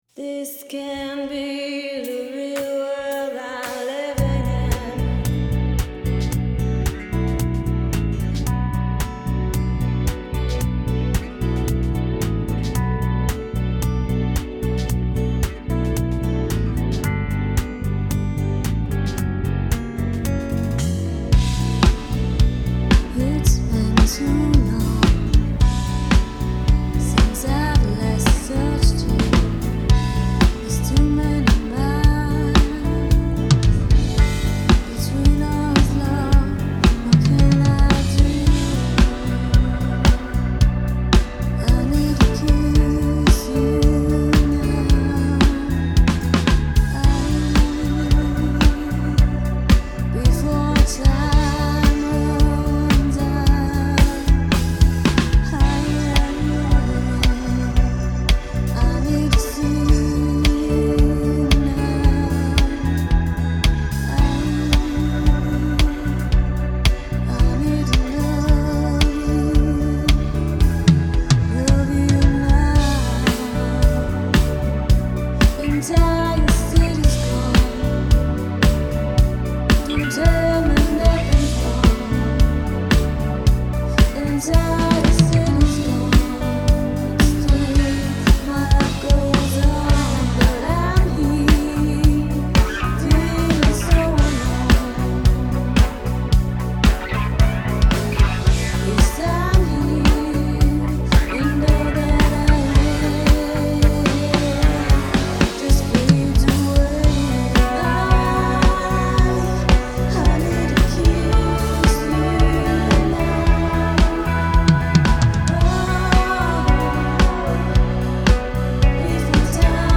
record professional drums on your songs
PEARL DRUMS
PORK PIE DRUMS
SABIAN CYMBALS